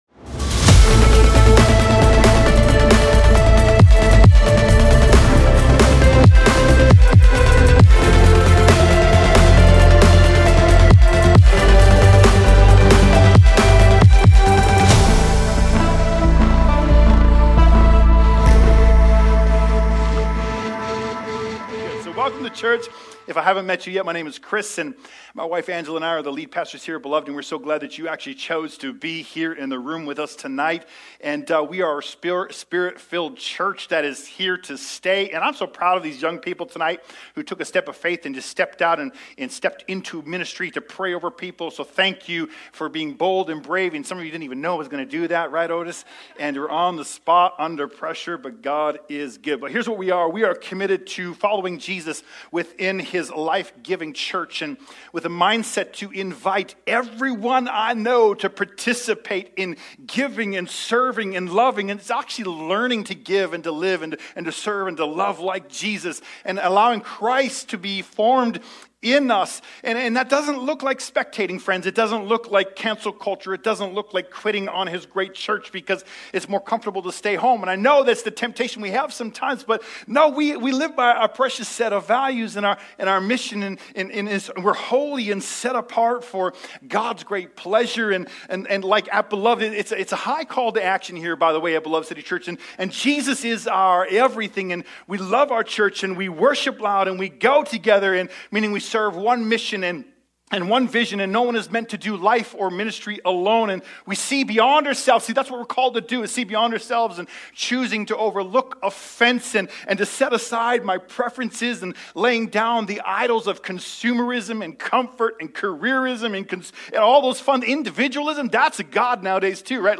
Sermons | Beloved City Church